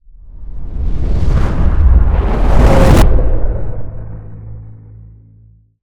cinematic_buildup_reverse_whoosh_02.wav